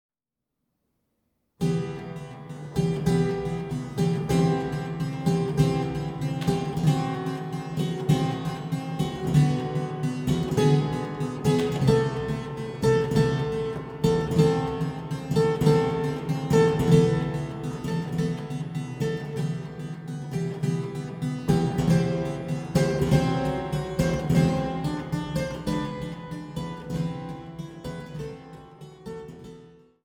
gespielt an der Trost-Orgel der Schlosskirche Altenburg